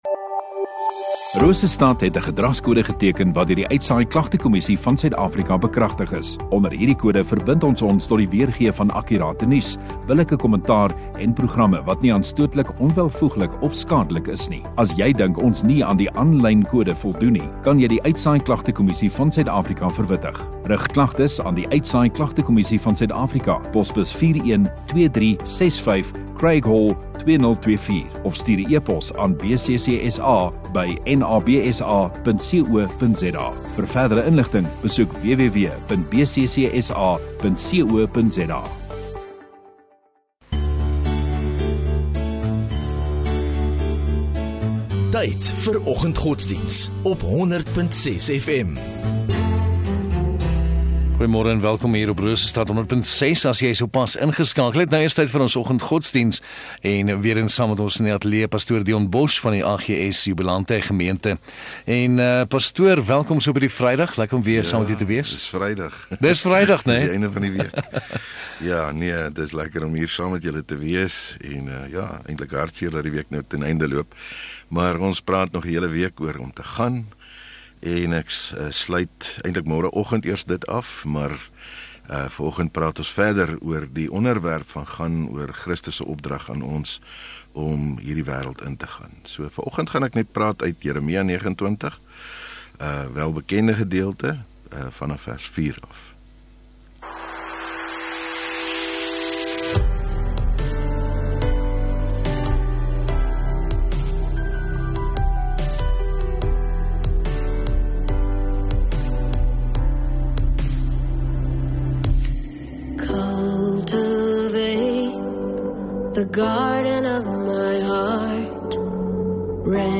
29 Sep Vrydag Oggenddiens